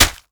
Flesh Chop 3 Sound
horror